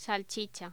Locución: Salchicha
locución
Sonidos: Voz humana